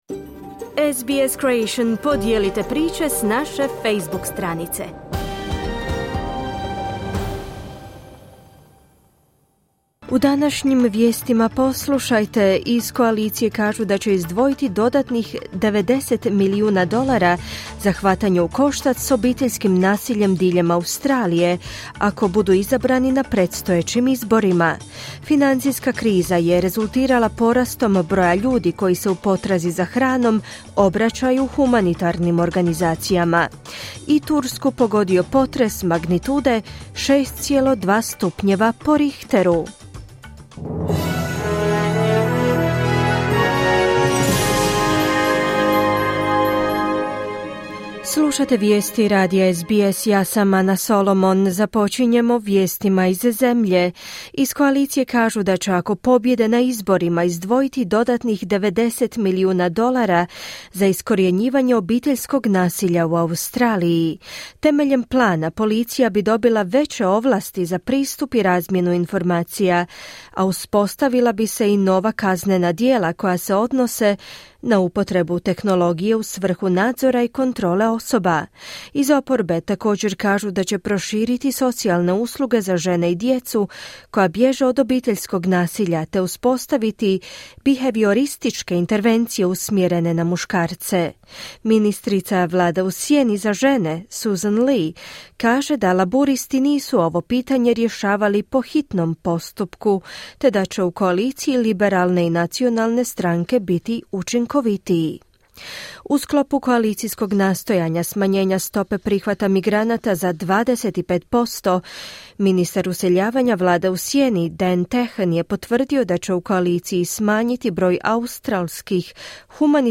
Vijesti radija SBS na hrvatskom jeziku.